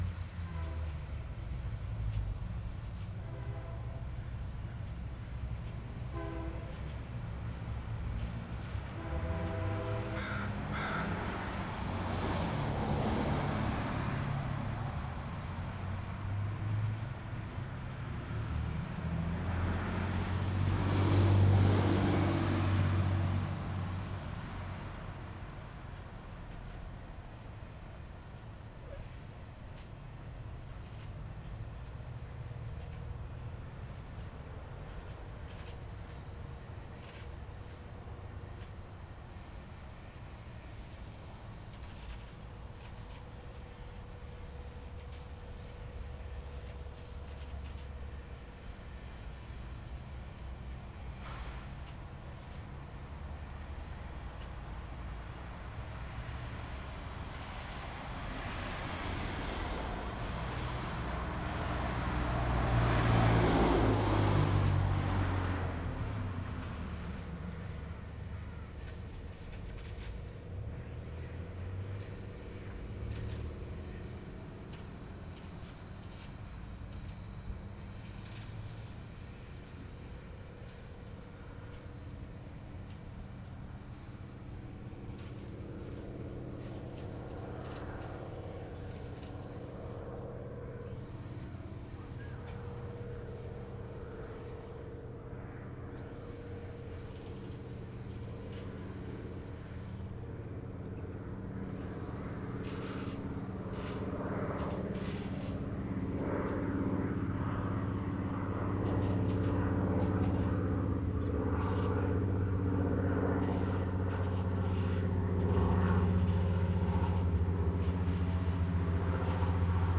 FOLDER 10 - North Burnaby (Recording by Barry Truax with an H2 Zoom Stereo Recorder)
9. West Coast Express horn at intro, cars until 0:35, car at 1:00, airplane at 1:30, car at 2:00, bird at 2:10, train whistle at 2:25, 3:00, child scream at 3:15, 3:20, 3:25, train whistle at 3:50, 4:00, horn at 5:00, car passes at 5:24, crow at 5:31, car at 6:28, 6:50, 7:11, car door closes at 7:15.